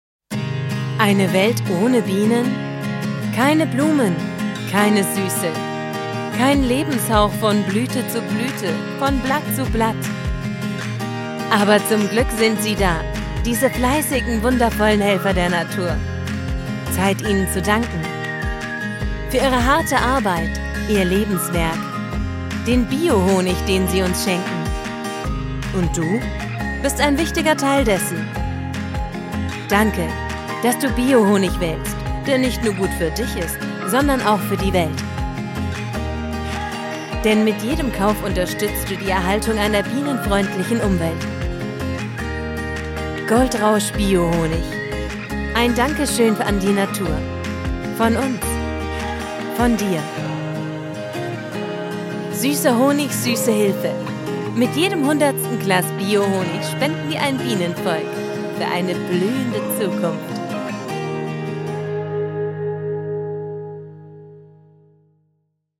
Hier ein paar Audio- und Video-Beispiele – von sinnlich, ernst oder sachlich über unbeschwert und heiter hin zu aufgebracht und verzweifelt.
Werbung & Commercials